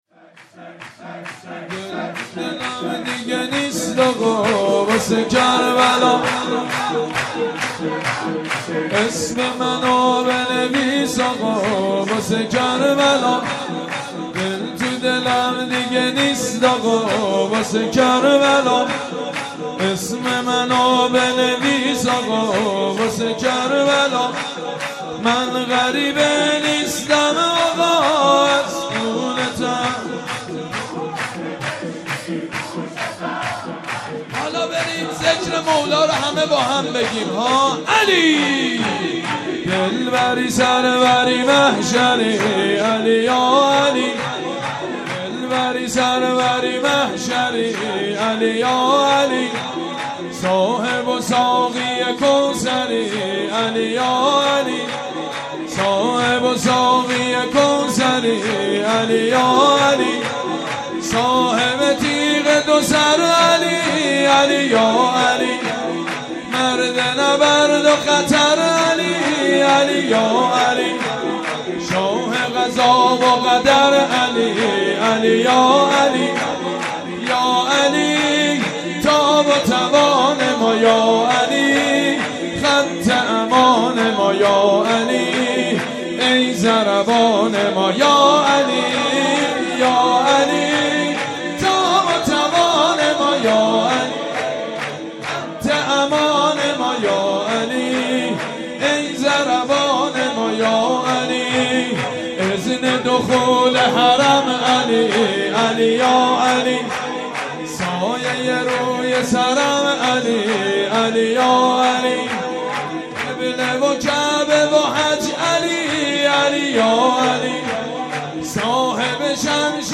مناسبت : ولادت حضرت فاطمه‌ زهرا سلام‌الله‌علیها
قالب : شور